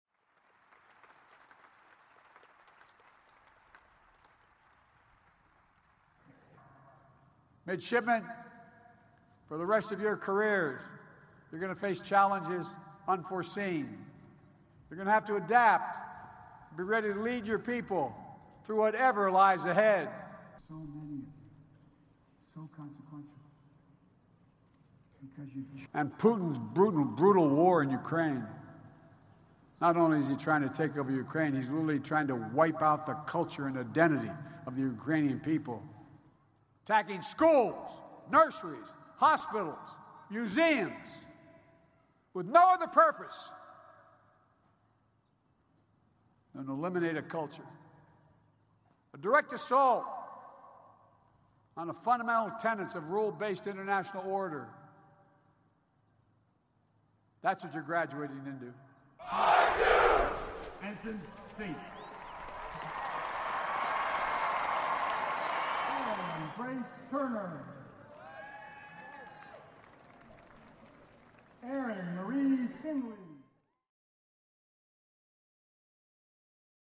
美国总统拜登向海军学院毕业生发表讲话